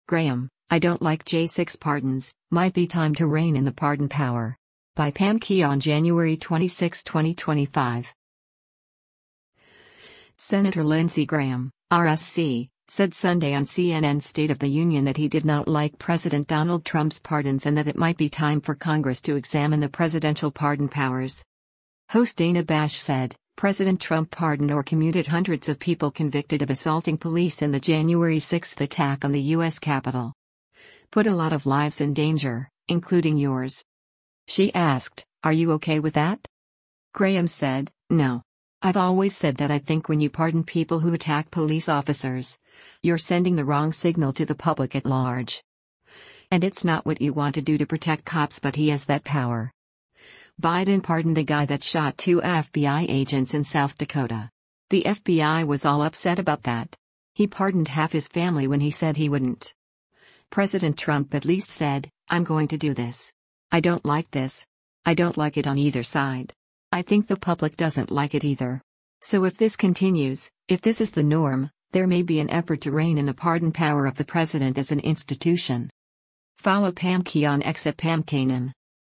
Senator Lindsey Graham (R-SC) said Sunday on CNN’s “State of the Union” that he did not like President Donald Trump’s pardons and that it might be time for Congress to examine the presidential pardon powers.